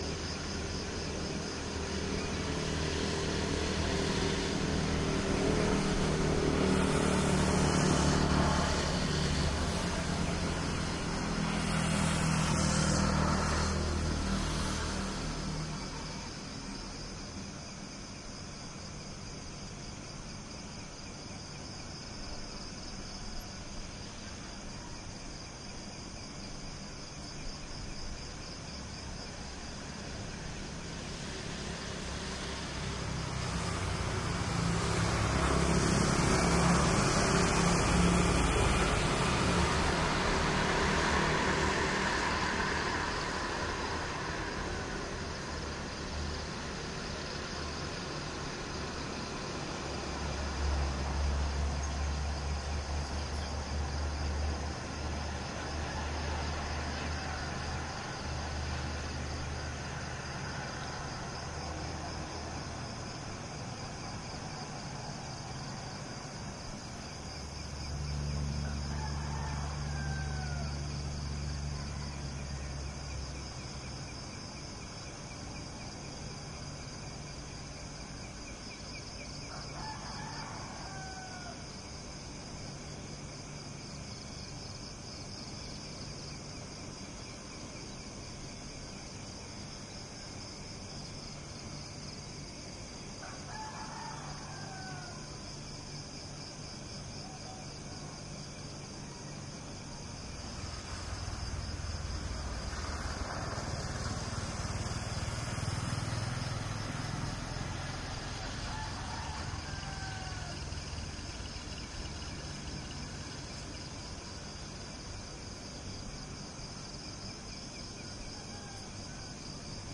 泰国 " 泰国小城清晨的氛围，从五楼的阳台看大卡车和鸡（有一些空调
描述：泰国小镇清晨的氛围，来自五楼阳台的大卡车和鸡（有一些空调，但混在低处） 用索尼D50进行现场录音。
Tag: 阳台 泰国 早晨 卡车 现场录音